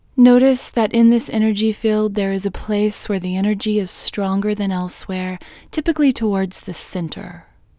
IN Technique First Way – Female English 4